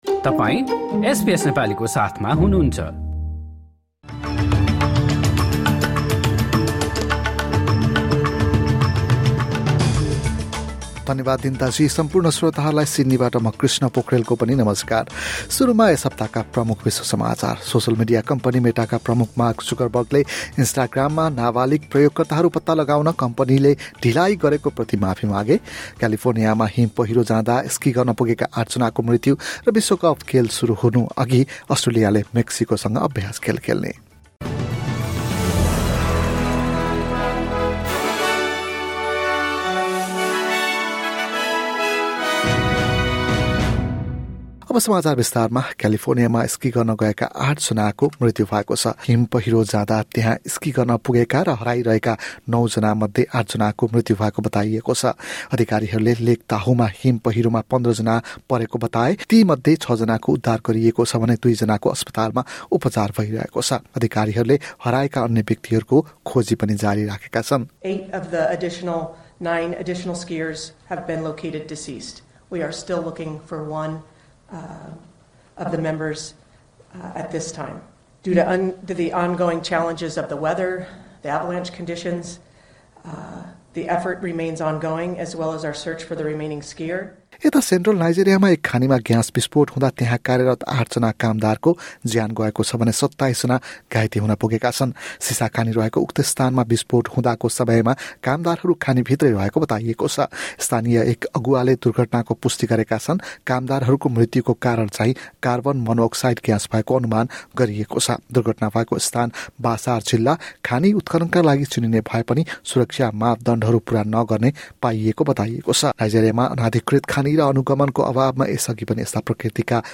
गत सात दिनका प्रमुख विश्व समाचार